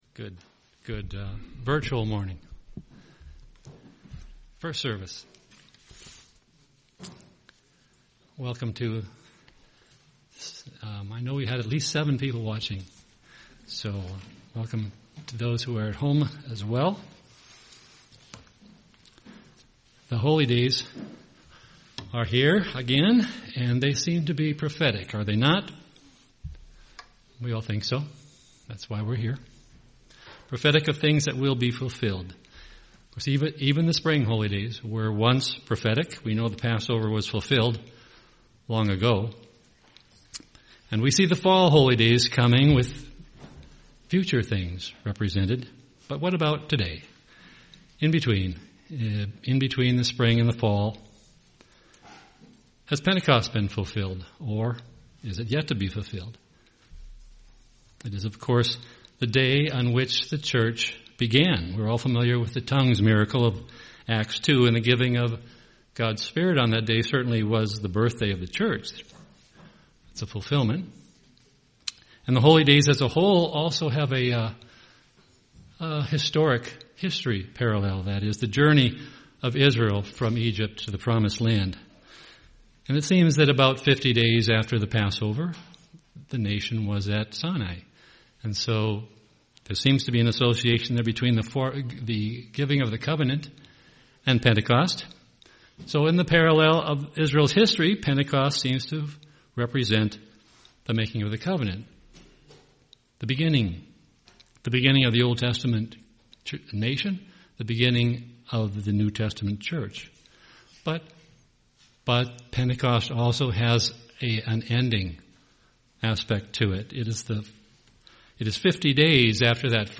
Sermons
Given in Twin Cities, MN